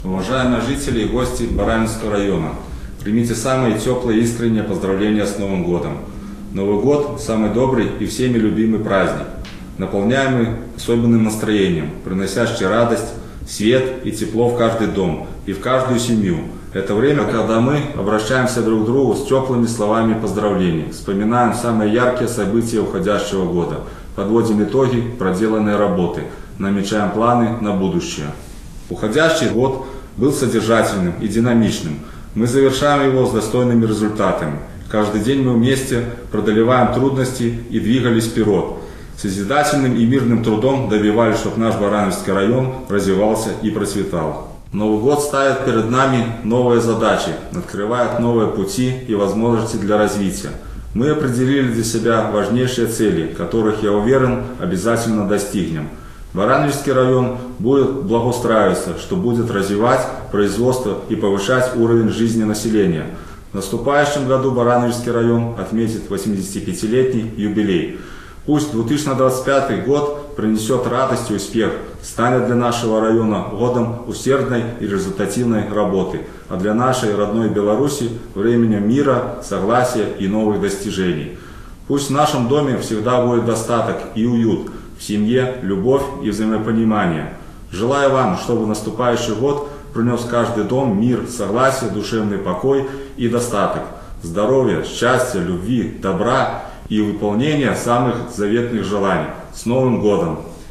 С Новым 2025–м годом жителей Барановичского региона поздравляет председатель райисполкома Роман Жук